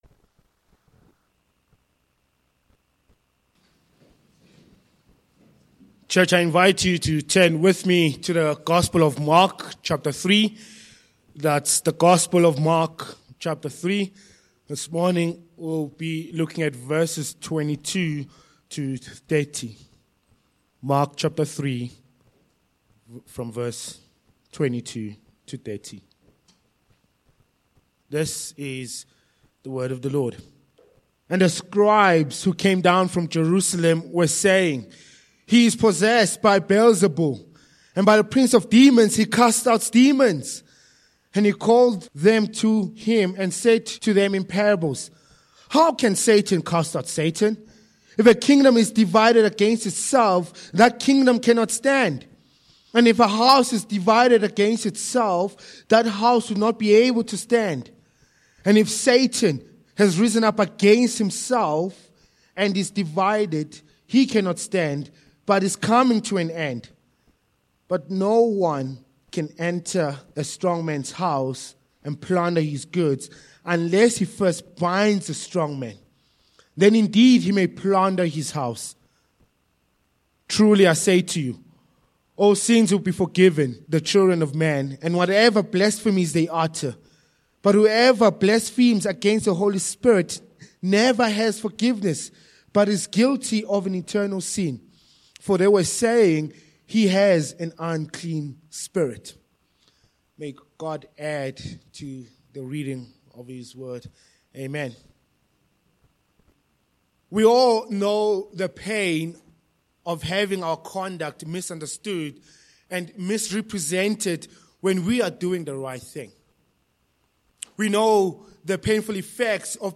Sermon
Service Type: Morning